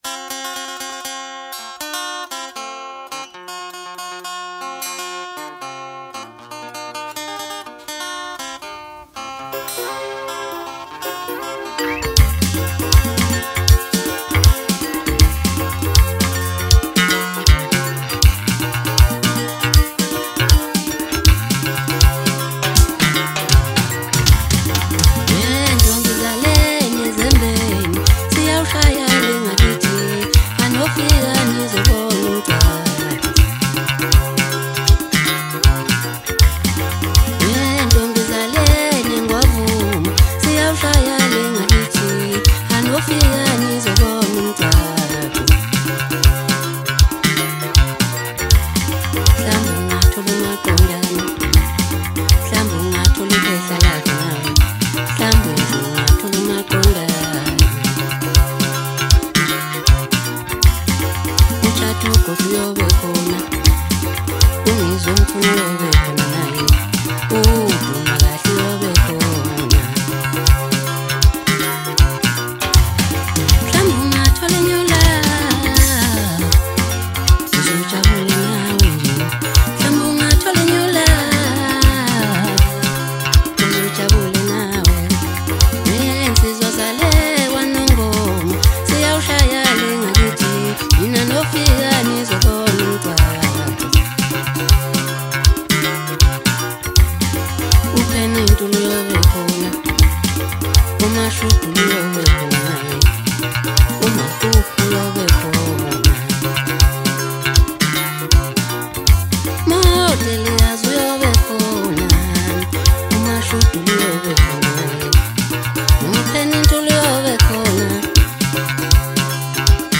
MASKANDI MUSIC